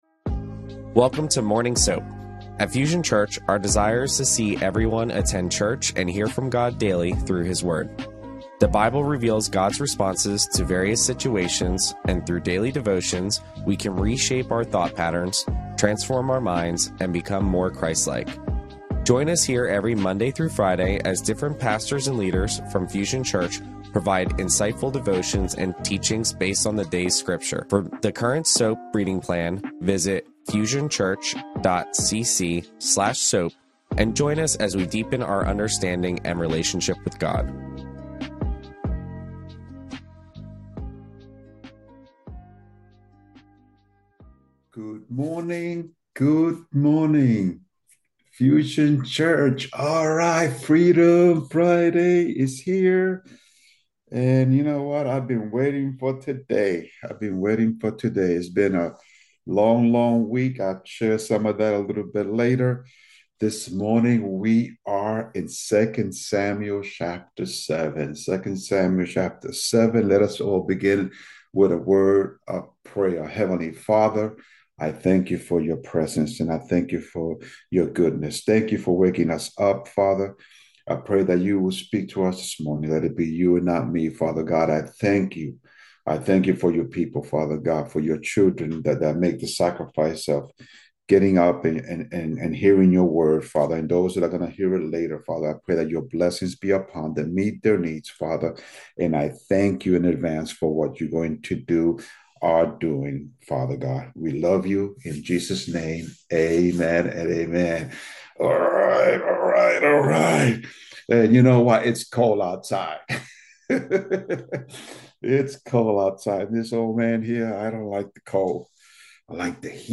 Fusion Church's Morning S.O.A.P. Bible Study happens at 6AM on Monday through Friday mornings on ZOOM. This 35-45min study focuses on the SOAP for the day from pastors and teachers of Fusion Church.